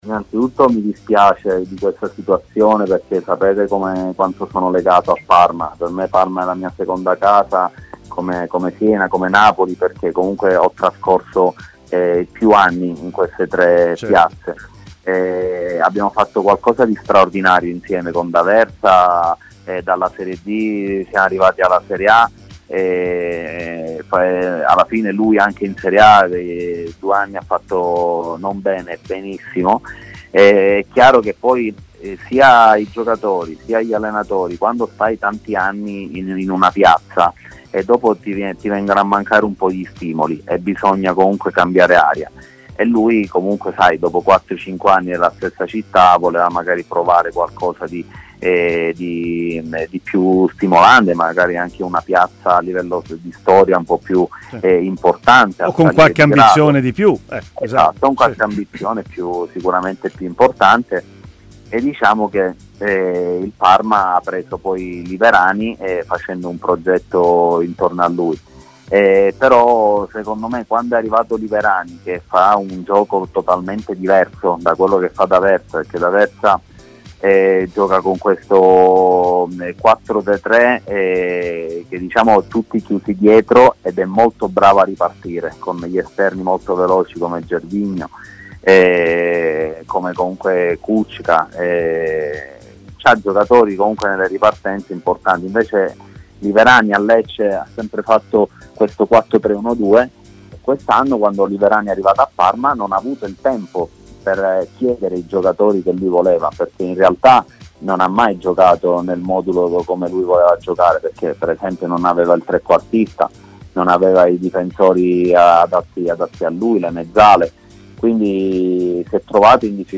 L'ex attaccante del Napoli Emanuele Calaiò è intervenuto in diretta ai microfoni di  Tmw Radio